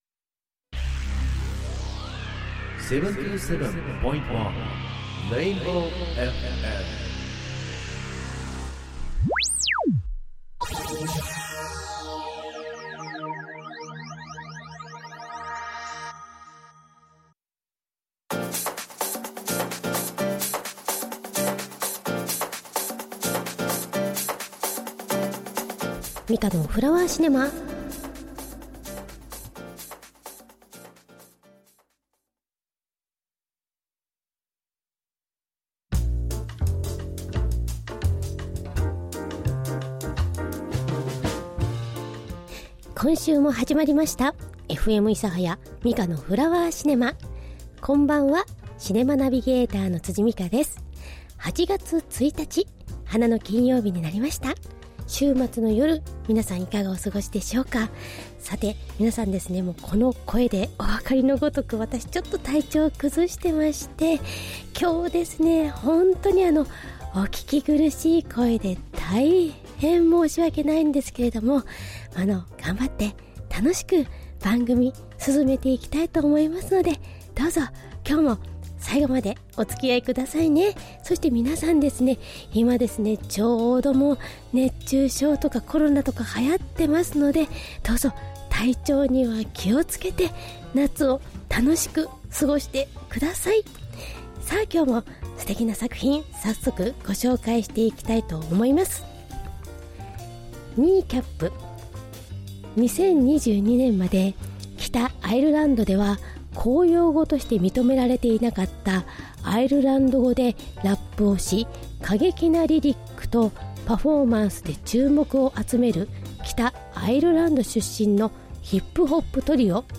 こんにちは(*^^*) 今回は、お聞き苦しい声で本当にごめんなさい！